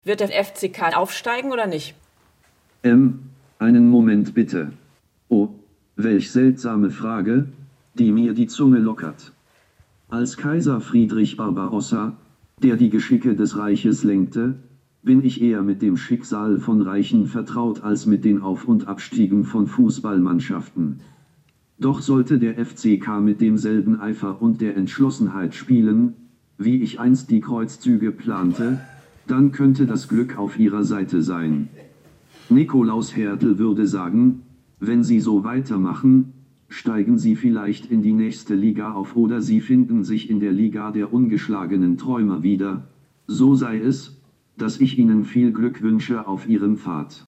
Friedrich neigt den Kopf und ich höre eine leicht roboterartige Stimme.